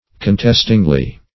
contestingly - definition of contestingly - synonyms, pronunciation, spelling from Free Dictionary Search Result for " contestingly" : The Collaborative International Dictionary of English v.0.48: Contestingly \Con*test"ing*ly\, adv.